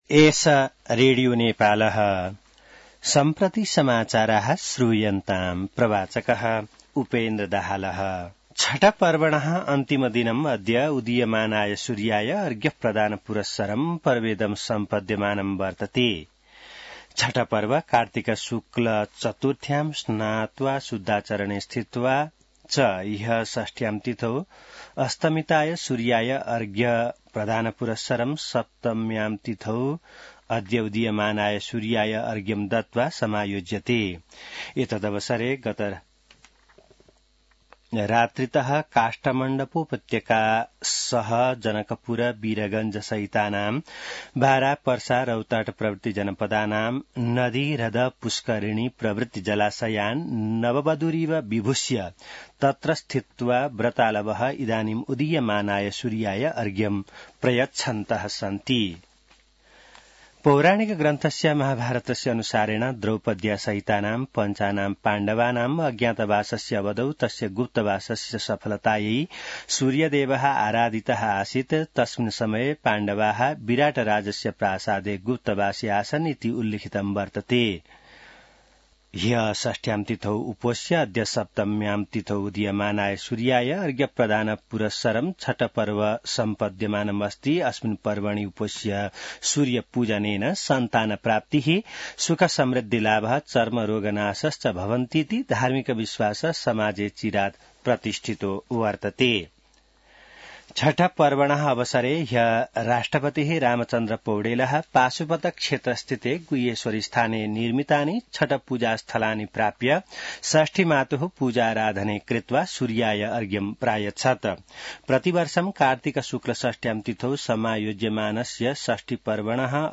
An online outlet of Nepal's national radio broadcaster
संस्कृत समाचार : २४ कार्तिक , २०८१